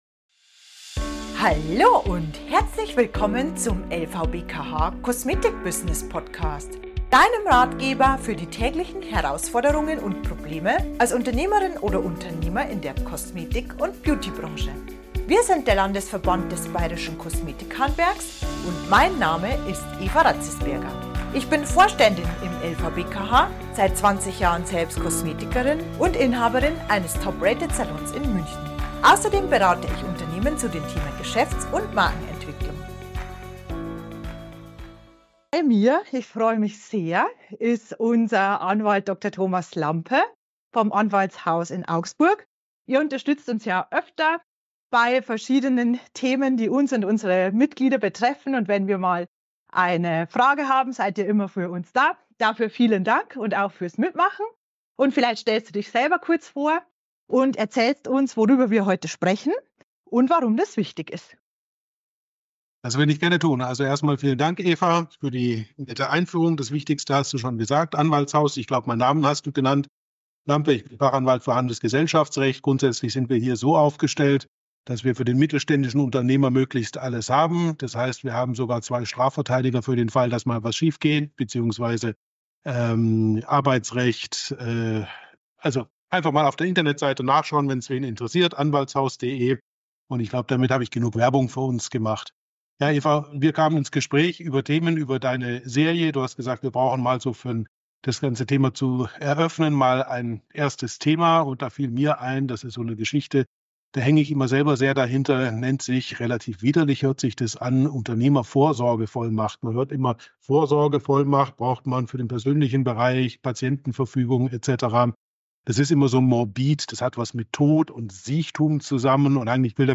Wie Unternehmer*innen sich für den Ernstfall absichern können - Ein Gespräch